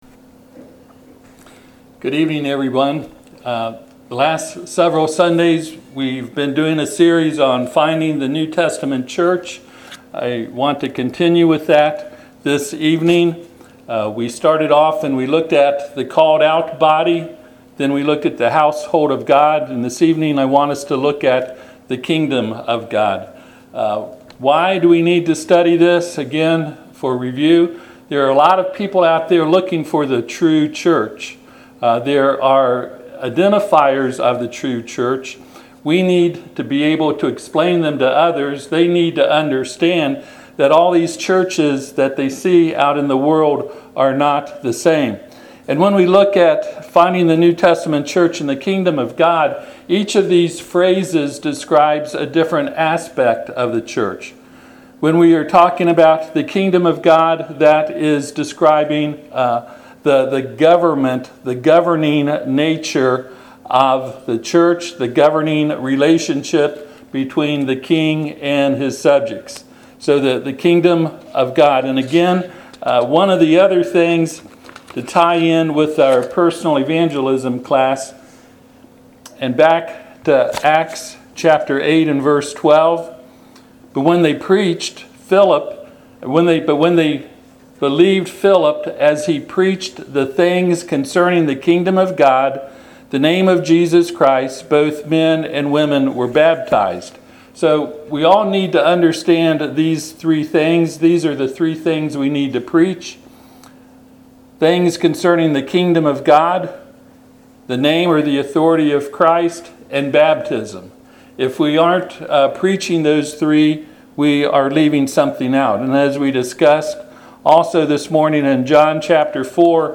Service Type: Sunday PM Topics: Authority , Church , Pattern